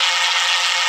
77 AIRY.wav